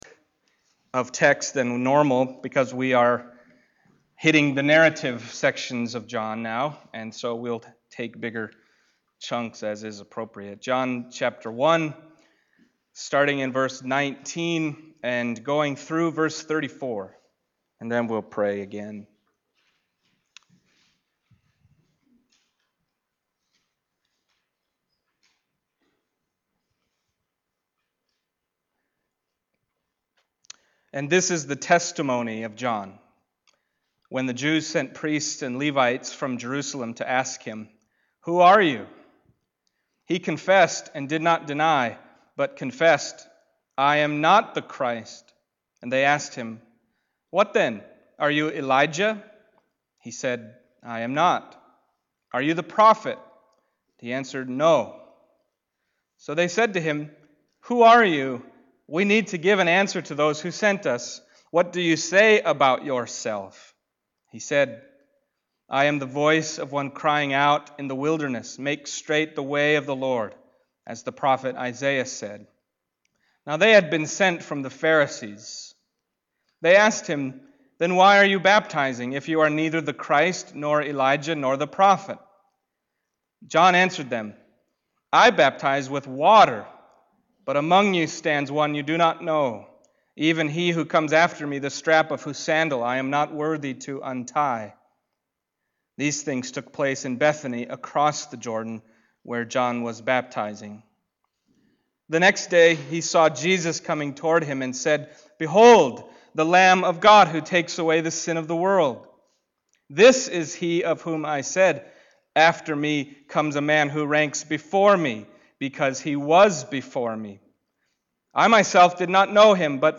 Passage: John 1:19-34 Service Type: Sunday Morning John 1:19-34 « We Have Seen His Glory